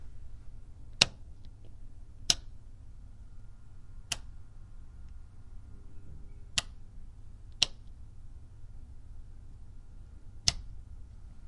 电灯开关
描述：开关灯
标签： 开关
声道立体声